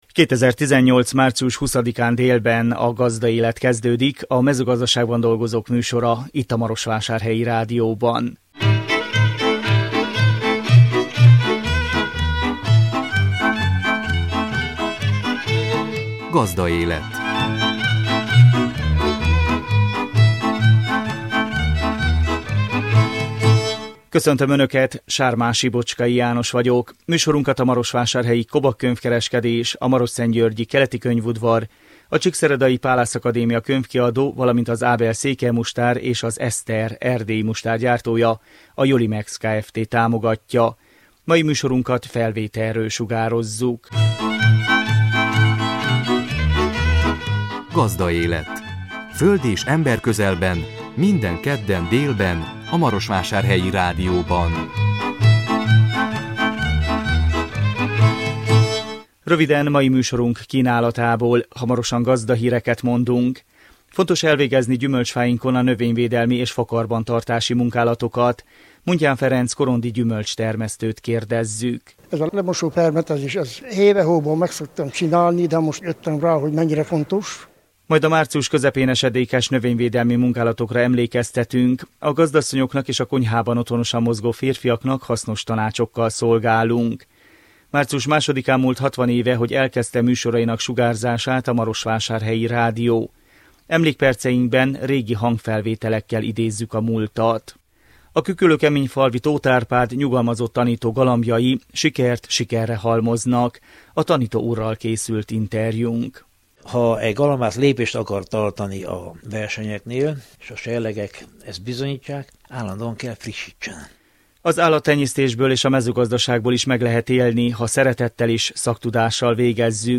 Emlékperceinkben régi hangfelvételekkel idézzük a múltat.
A tanító úrral készült interjúnk.